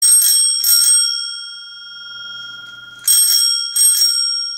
Fahrradklingel klingelton kostenlos
Kategorien: Soundeffekte
Fahrradklingel.mp3